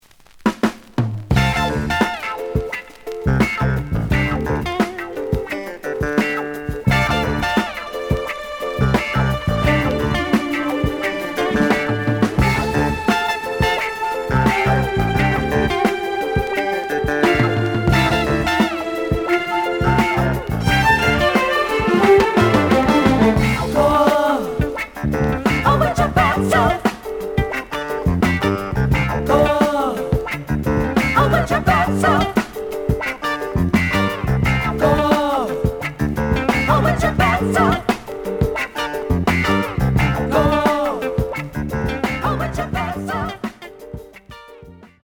The audio sample is recorded from the actual item.
●Genre: Soul, 70's Soul
Slight edge warp. But doesn't affect playing. Plays good.)